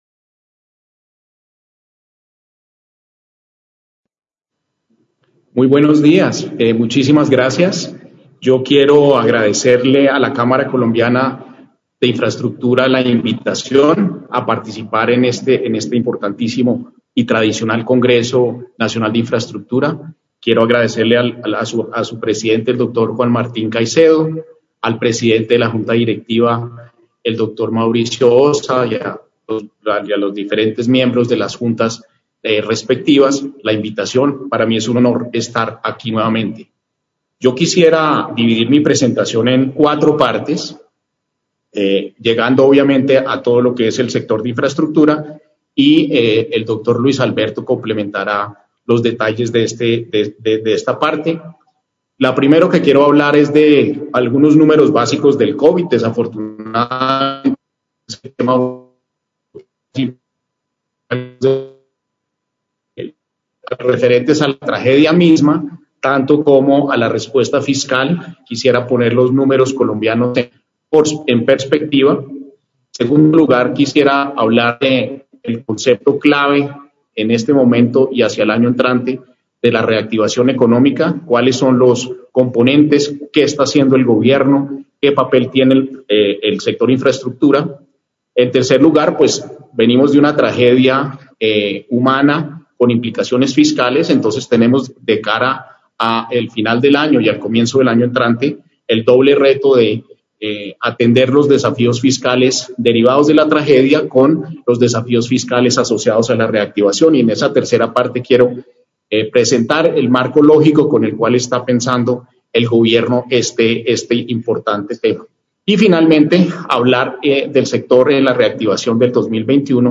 Intervención del Ministro de Hacienda en el Congreso Colombiano de la infraestructura en el panel Infraestructura Motor de Reactivación Económica